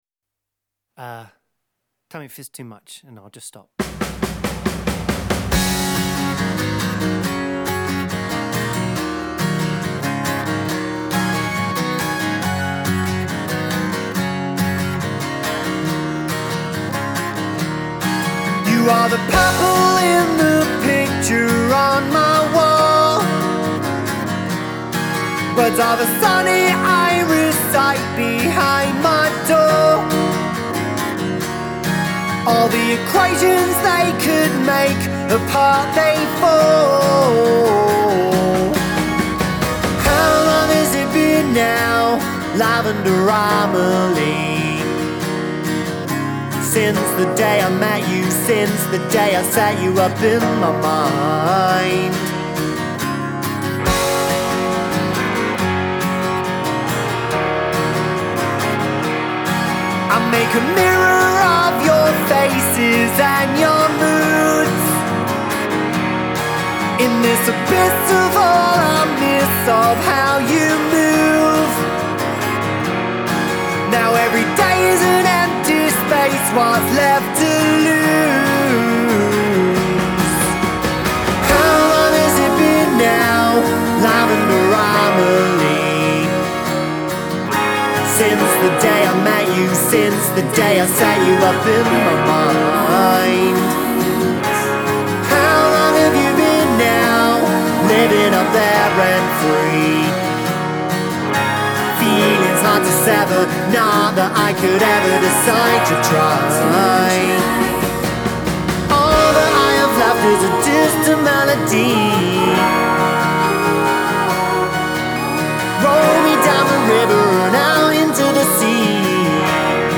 Genre : Alternative, Indie